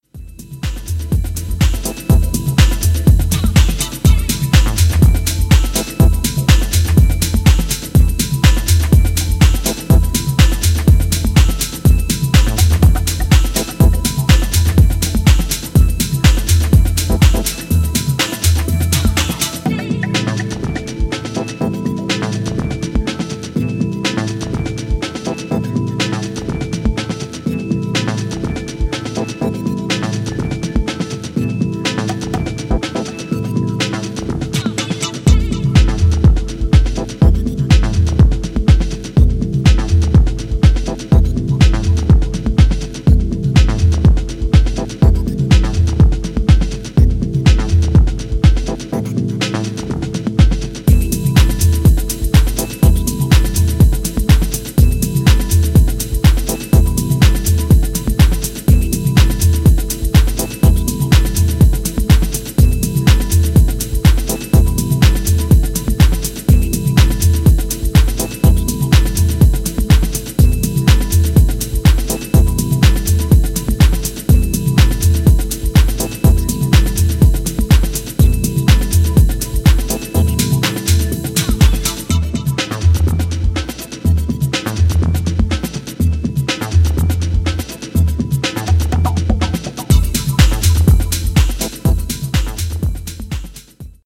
ジャジーコードのブレイクビーツ・ハウス